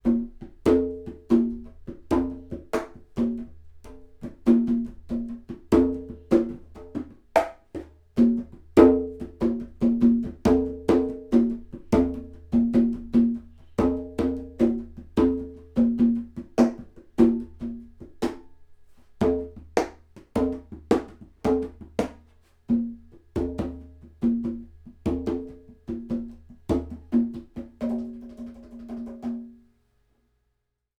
These are the raw Mid and Side files.
Side Congas
Side_Congas.wav